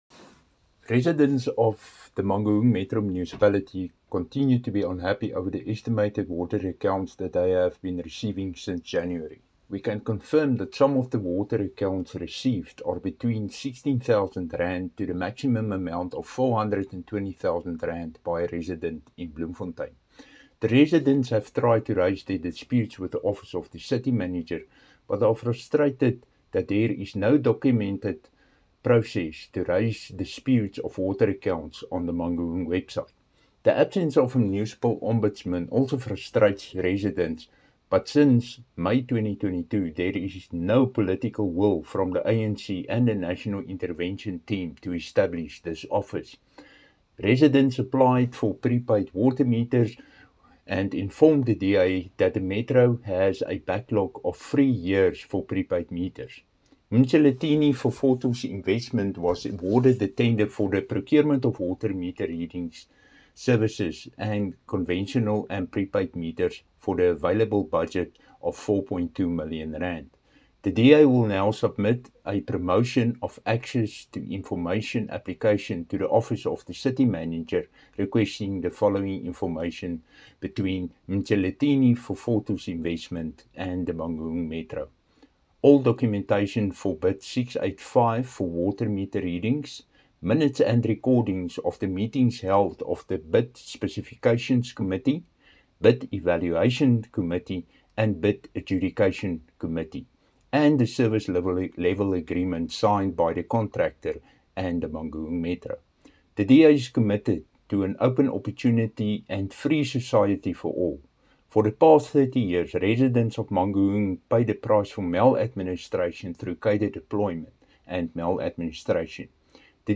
Afrikaans soundbites by Cllr Dirk Kotze and Sesotho by Cllr David Masoeu.